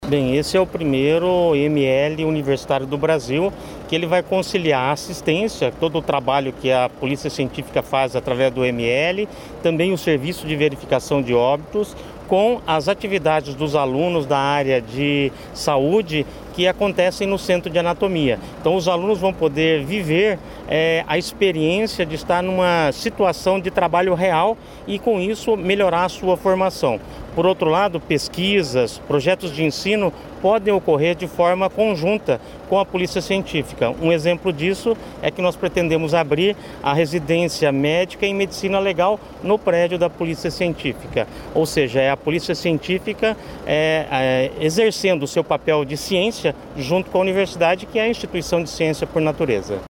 Sonora do reitor da UEPG, Miguel Sanches Neto, sobre a nova unidade da Polícia Científica em Ponta Grossa